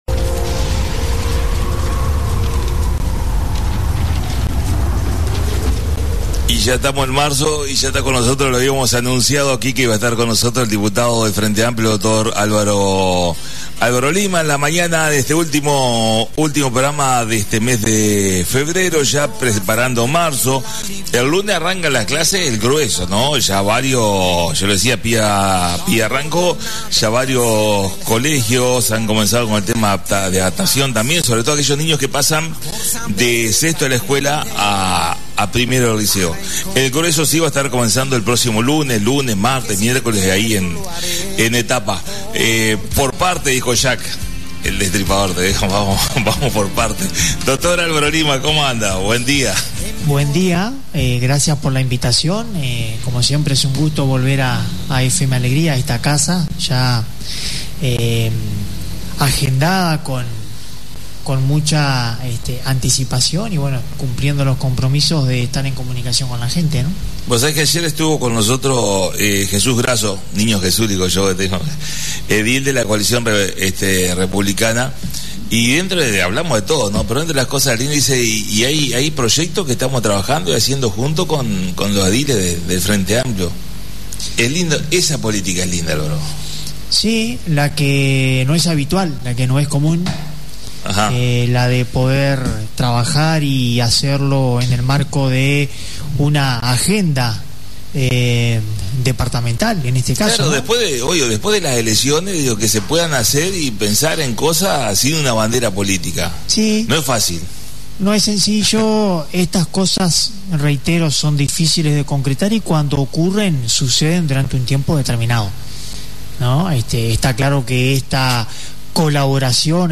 La visita a la Radio del Dr Álvaro Lima Diputado por el Frente Amplio y una puesta al día de todo en lo que viene trabajando junto a su equipo.